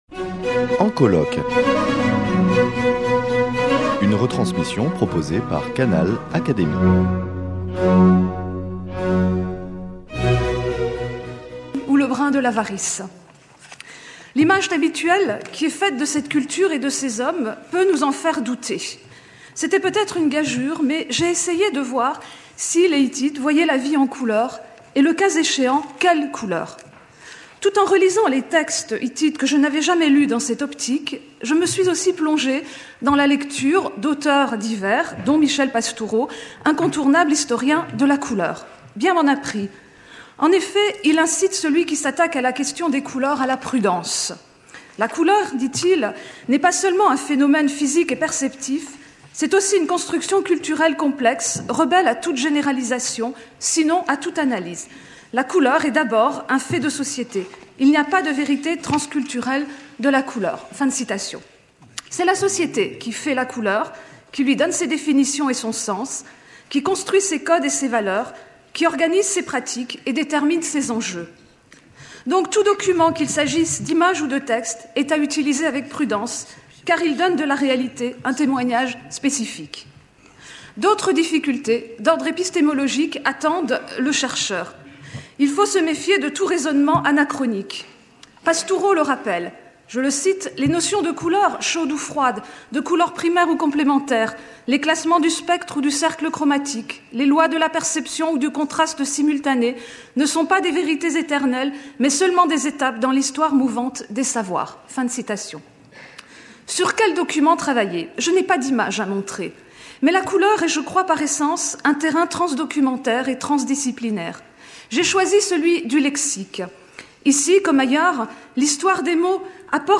Voir et concevoir la couleur en Asie , un colloque interdisciplinaire